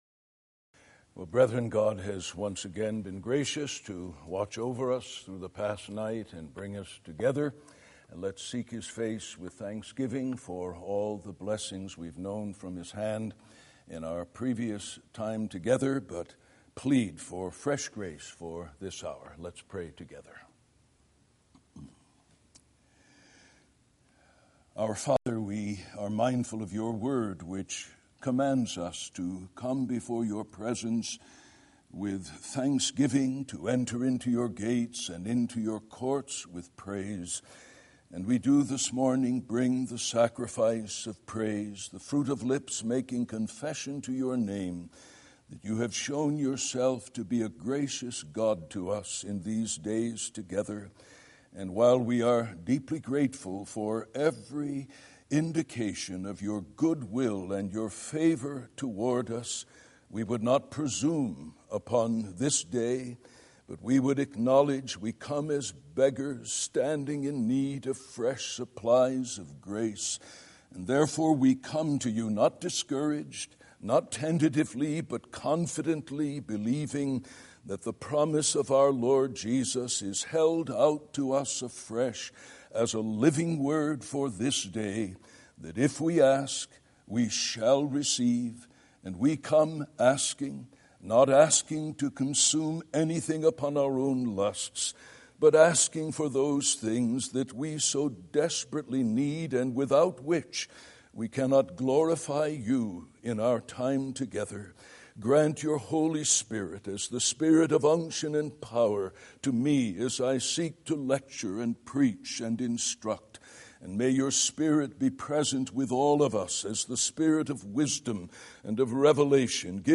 Unit+01+Lecture+09.mp3